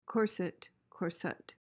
PRONUNCIATION:
(KOR-sit/suht)